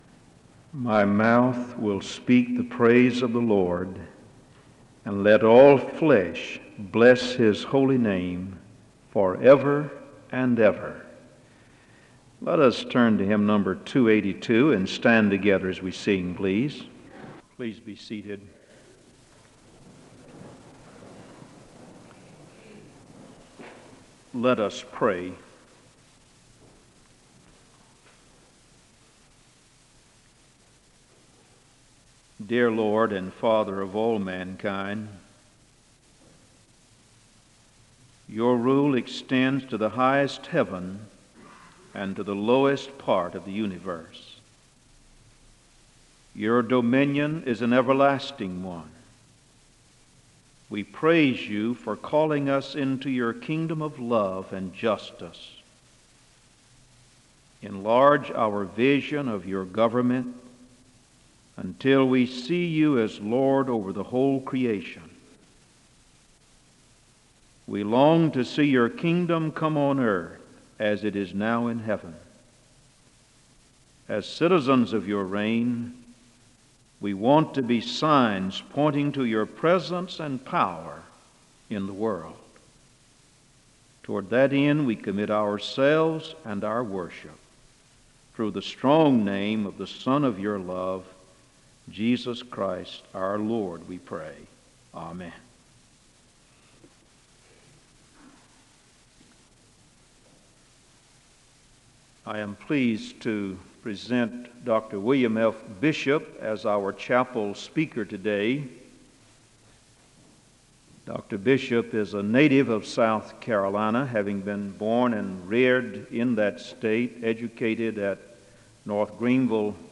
The service begins with a word of prayer (00:00-01:38).
The choir sings a song of worship (02:57-06:10).
SEBTS Chapel and Special Event Recordings SEBTS Chapel and Special Event Recordings